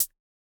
Index of /musicradar/retro-drum-machine-samples/Drums Hits/Tape Path B
RDM_TapeB_MT40-ClHat.wav